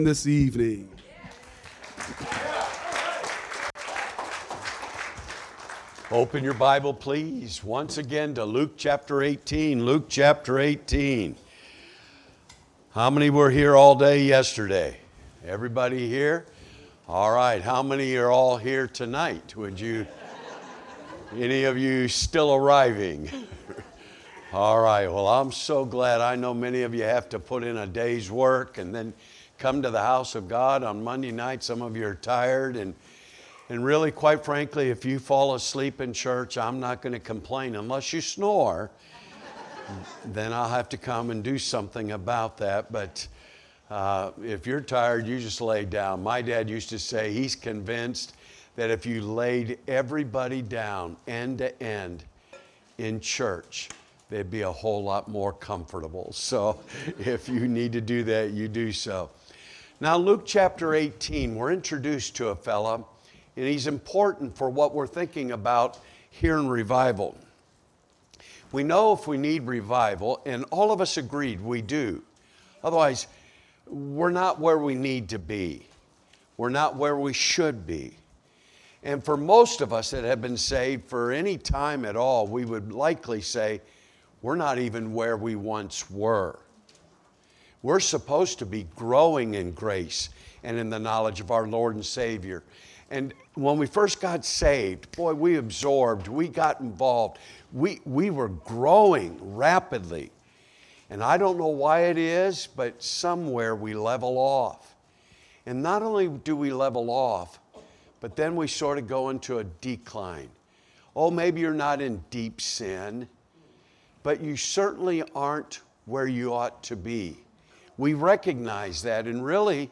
Monday Evening Revival Service